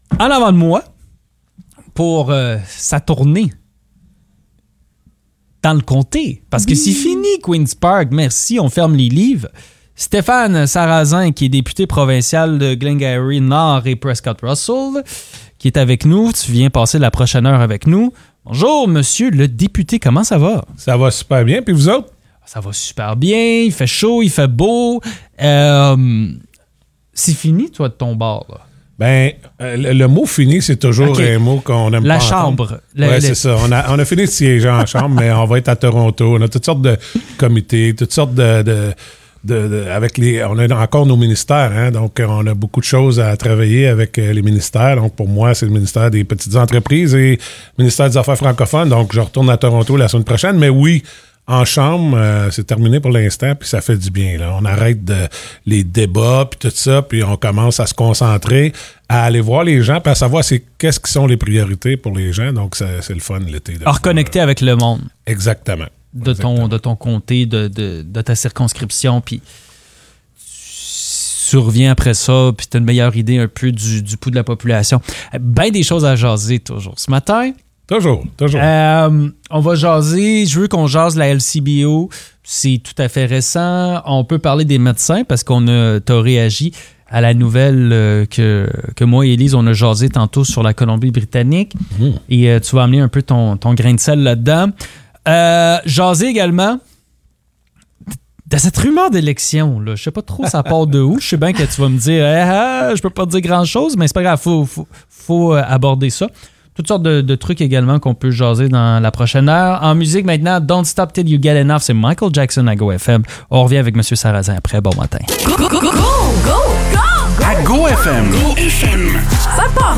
Le député provincial de Glengarry-Prescott-Russell, Stéphane Sarrazin, est venu passer une heure avec nous en studio. On lui a parlé de la grève possible à la LCBO, des rumeurs d'élections provinciales, des changements au niveau du transport scolaire dans la région et beaucoup plus.